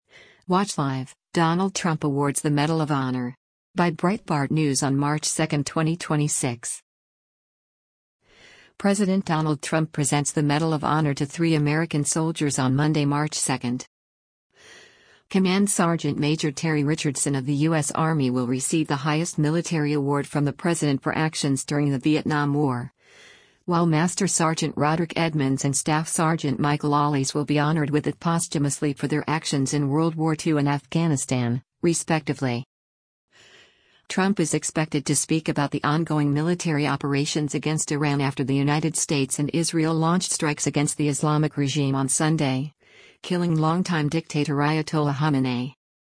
President Donald Trump presents the Medal of Honor to three American soldiers on Monday, March 2.